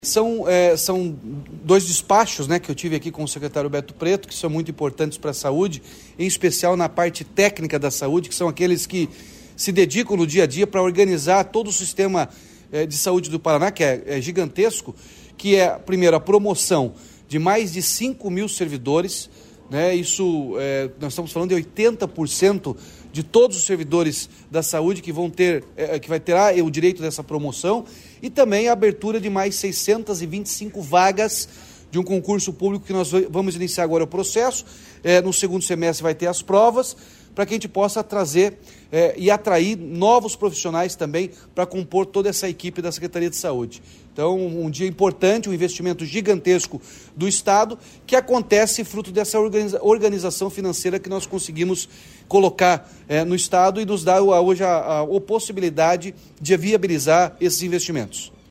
Sonora do governador Ratinho Junior sobre a autorização de concurso para contratação de 625 profissionais para a saúde do Paraná